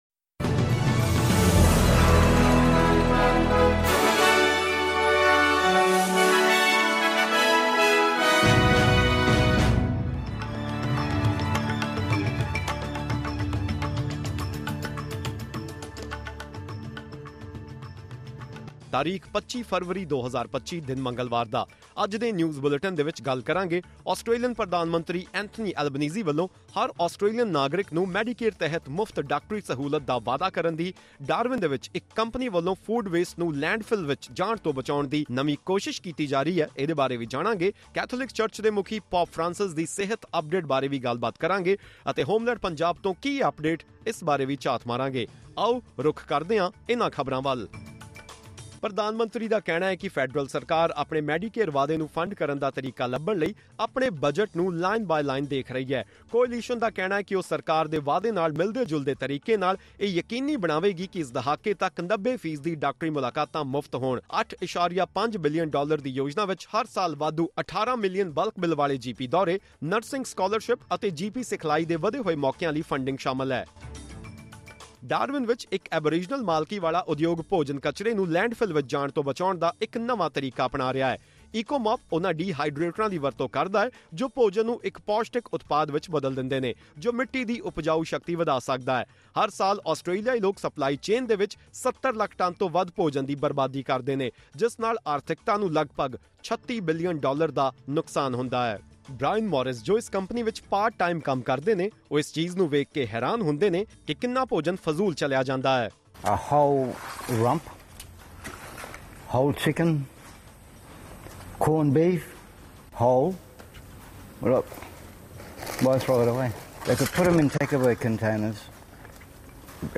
ਖਬਰਨਾਮਾ: ਫੈਡਰਲ ਸਰਕਾਰ ਆਪਣੇ ਮੈਡੀਕੇਅਰ ਵਾਅਦੇ ਨੂੰ ਫੰਡ ਮੁਹੱਈਆ ਕਰਨ ਲਈ ਤਰੀਕੇ ਲੱਭਣ ਦੀ ਕੋਸ਼ਿਸ਼ ਵਿੱਚ